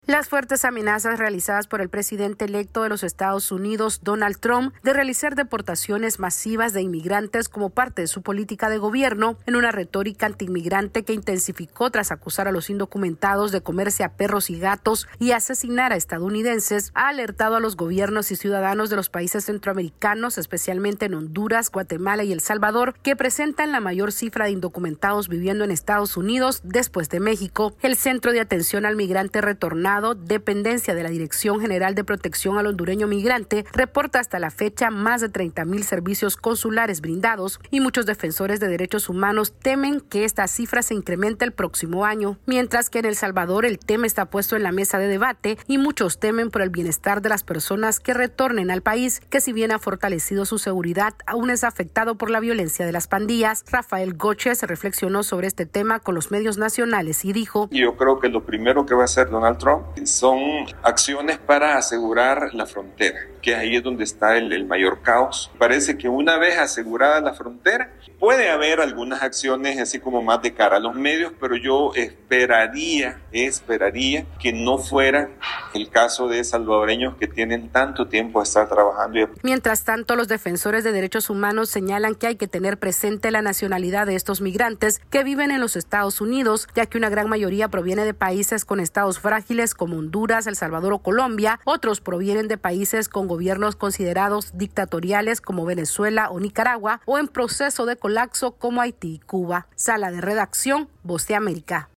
AudioNoticias
En Centroamérica crece la preocupación ante la posible deportación masiva de inmigrantes que se podría dar luego del cambio de gobierno en Estados Unidos. Esta es una actualización de nuestra Sala de Redacción.